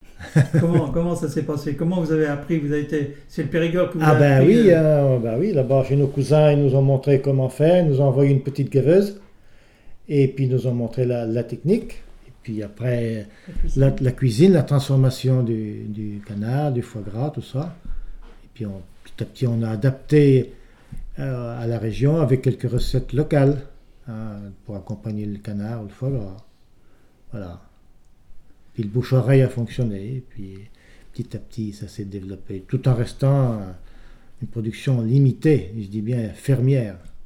Il provient de Saint-Gervais.
Catégorie Témoignage ( mémoire, activité,... )